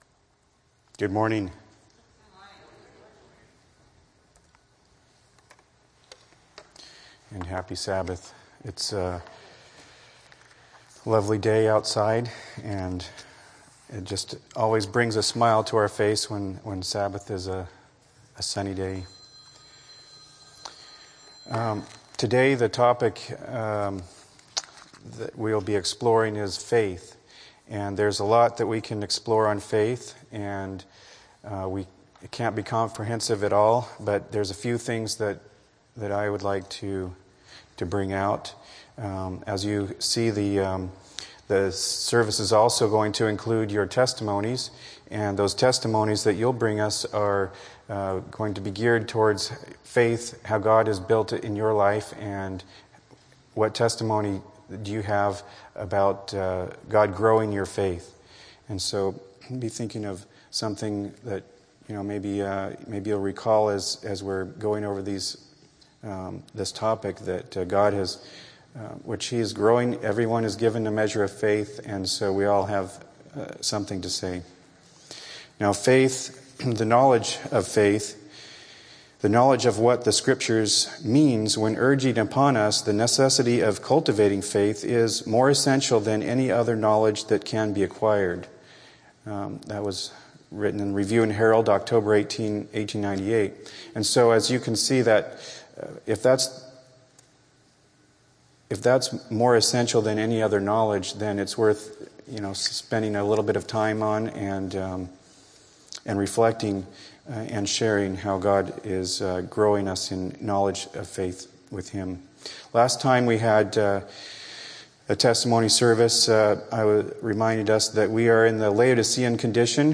Sabbath Fellowship Group